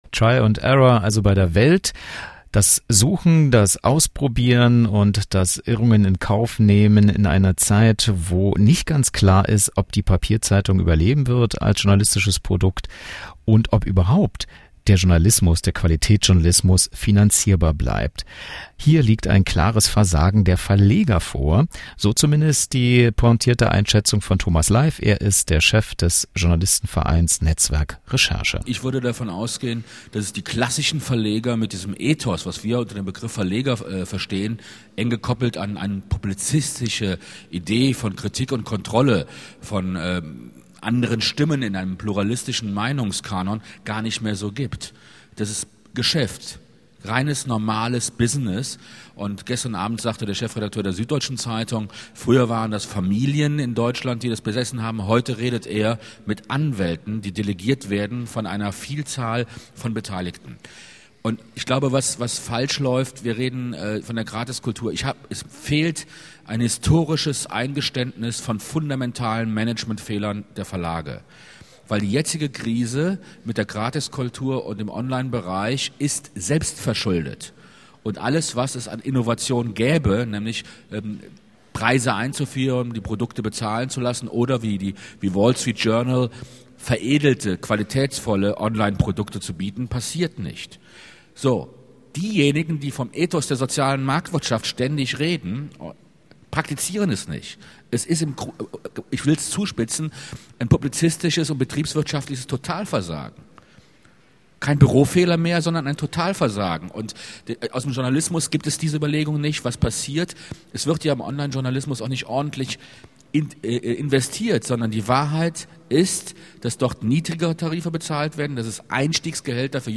Interviews/Statements/Diskussionsausschnitte: